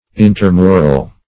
Intermural \In`ter*mu"ral\, a.